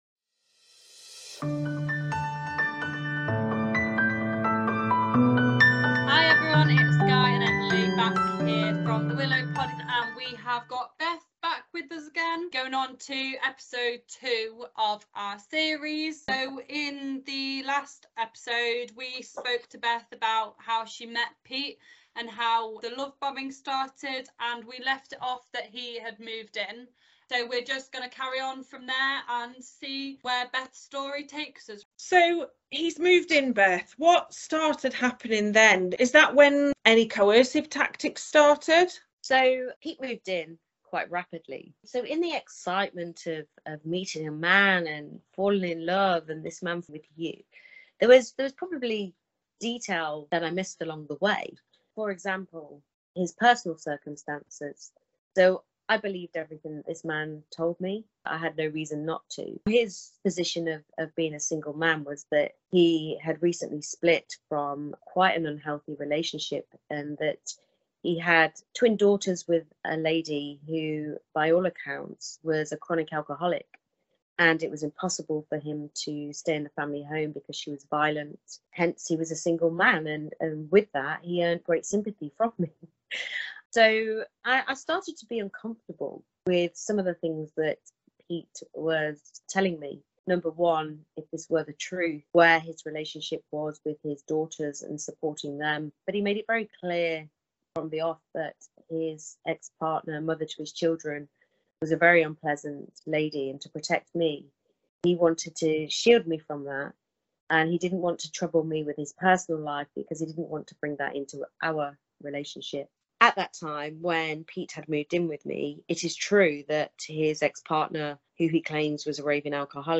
Interview with a victim episode 2